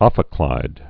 (ŏfĭ-klīd)